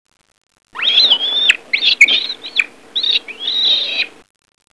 Monkey
MONKEY.wav